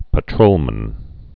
(pə-trōlmən)